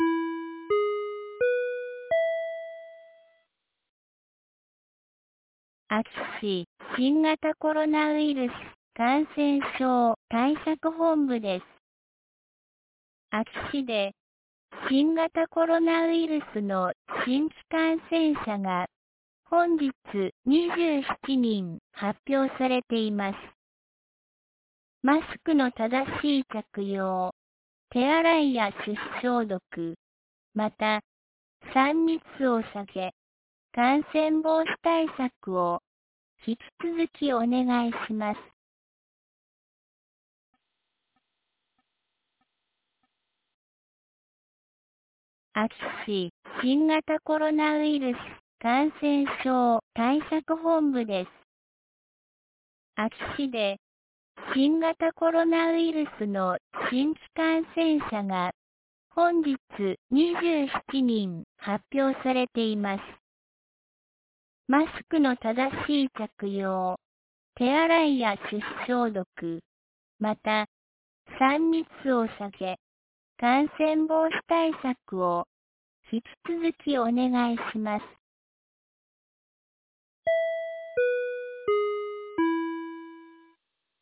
2022年09月21日 17時06分に、安芸市より全地区へ放送がありました。